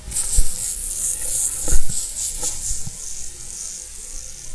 tarantula_sound.wav